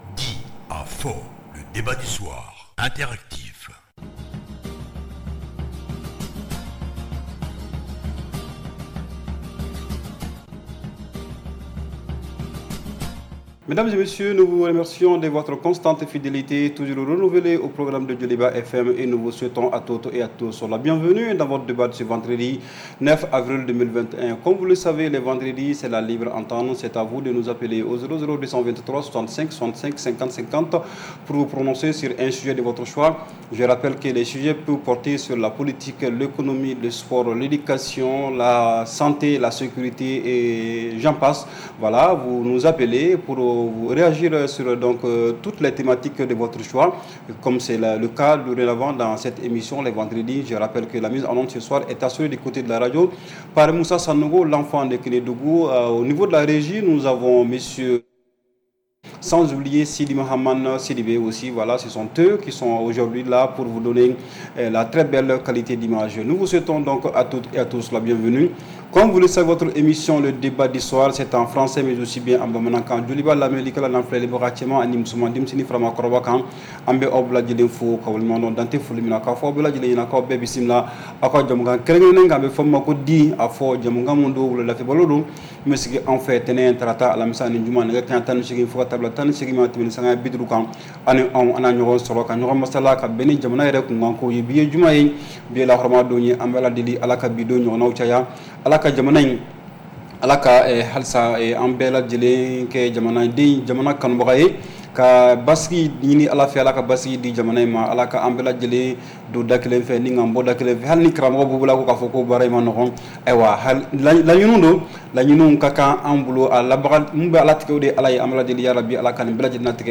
REPLAY 09/04 – « DIS ! » Le Débat Interactif du Soir